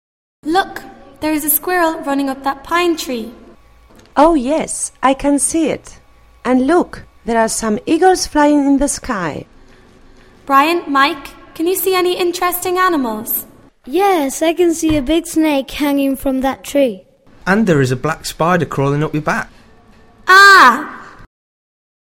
Descripci�n: El video representa la conversaci�n entre varias personas (protagonistas que aparecen y texto asociado se muestra m�s abajo).